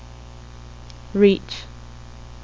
dysarthria / non_dysarthria_female /FC02_Session3_0843.wav